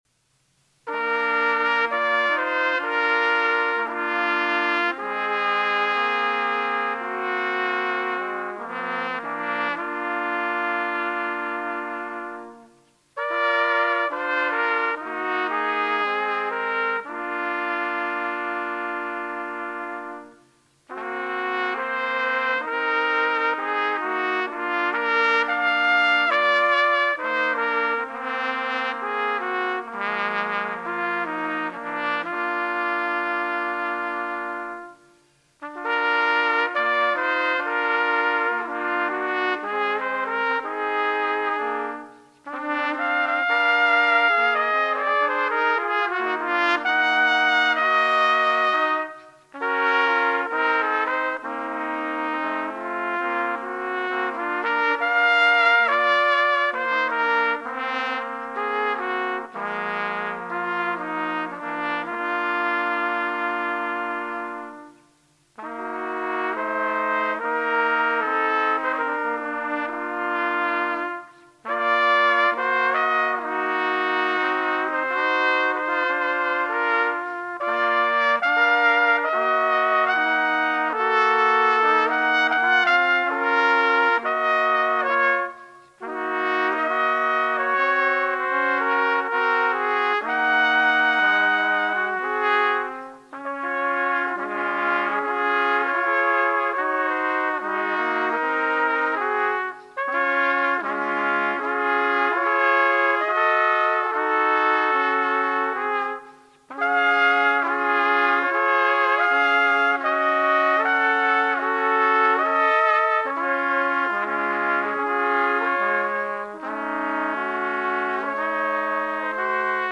Solo Trumpet
Willwerth Essays for Unaccompanied Trumpet